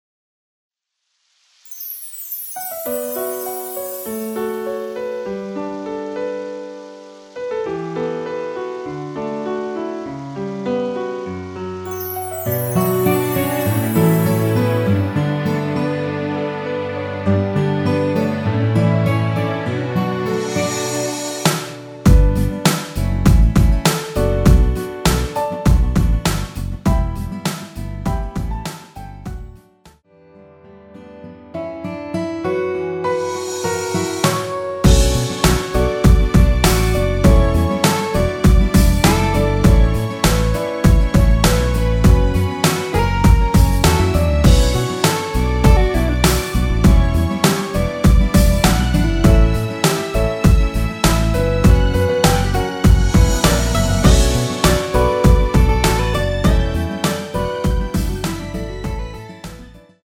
Bm
◈ 곡명 옆 (-1)은 반음 내림, (+1)은 반음 올림 입니다.
앞부분30초, 뒷부분30초씩 편집해서 올려 드리고 있습니다.
중간에 음이 끈어지고 다시 나오는 이유는